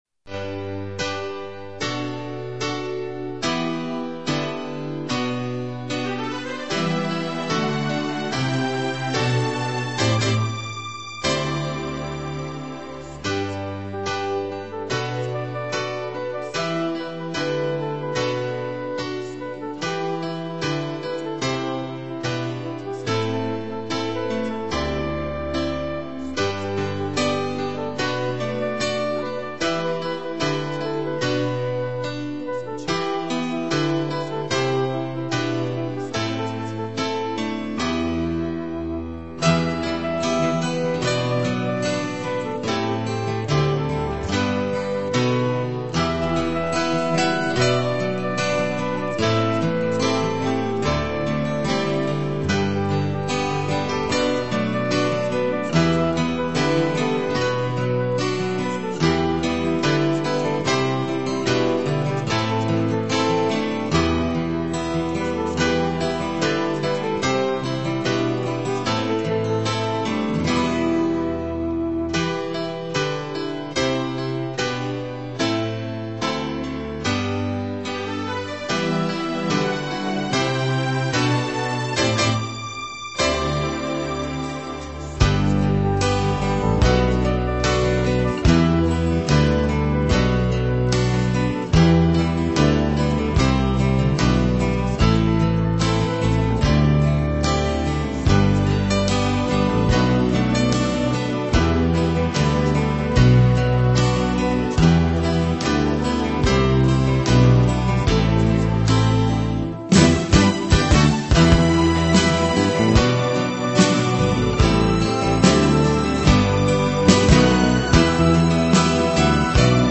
找到的这个伴奏虽然有主旋律音，不过效果已经算是好的啦！